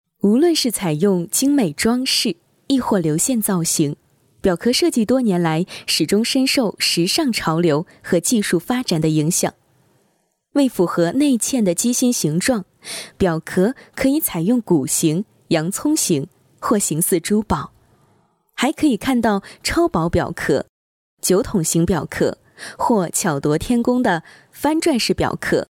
Female Professional Voice Over Talent | VoicesNow Voiceover Actors